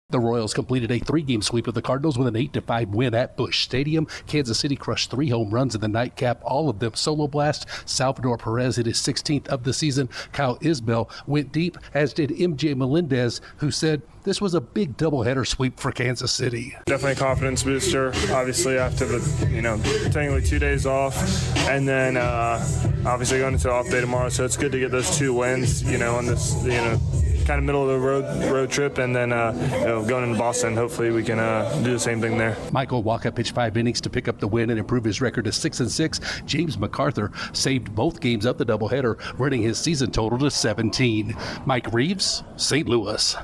The Royals take two from their state rivals. Correspondent